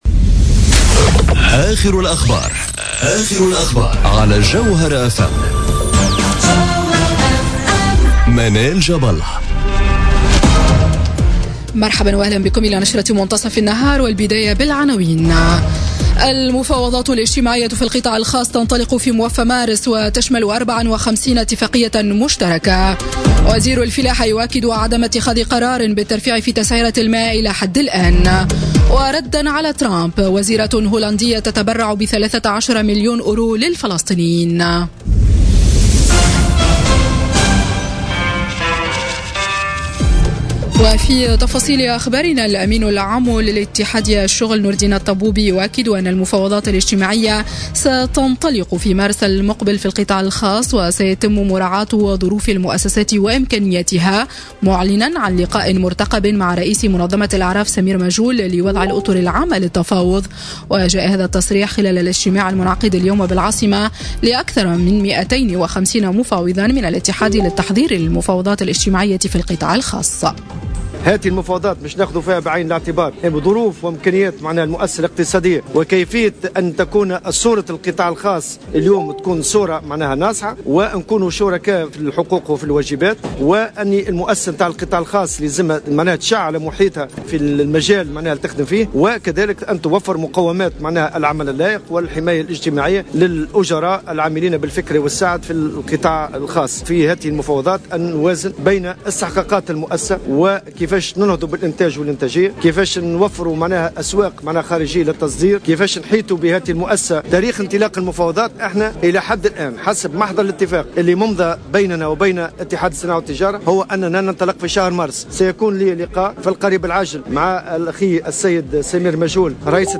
نشرة أخبار السابعة صباحا ليوم الإثنين 5 فيفري 2018